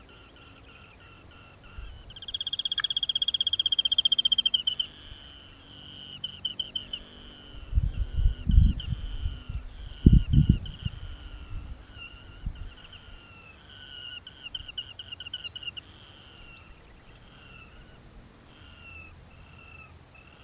Ocellated Crake (Rufirallus schomburgkii)
Al menos tres ejemplares vocalizando a mi alrededor tras hacer playback!
Detailed location: Reserva Natural Silvestre Parque Federal Campo San Juan
Condition: Wild
Certainty: Recorded vocal